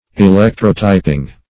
Electrotyping \E*lec"tro*ty`ping\, n.